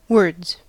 Ääntäminen
US : IPA : [wɝdz] UK : IPA : /ˈwɜːdz/